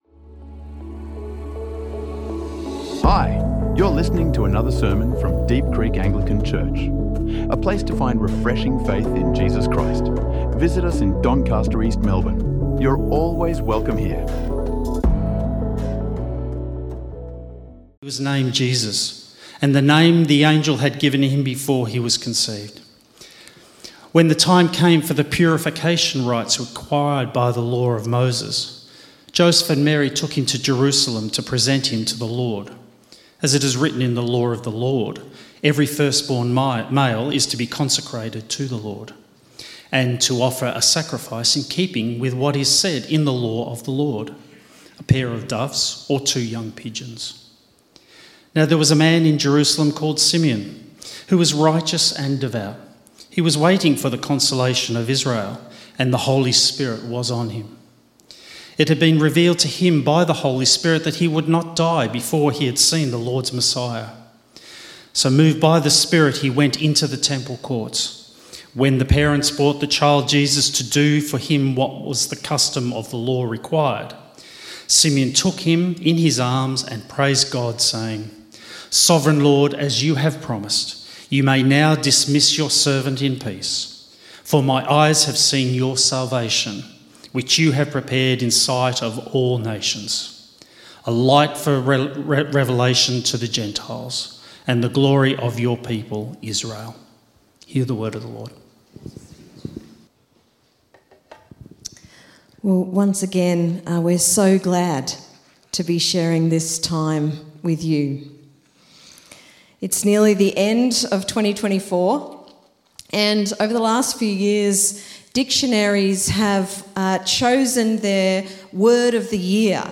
Treasuring God’s Peace | Sermons | Deep Creek Anglican Church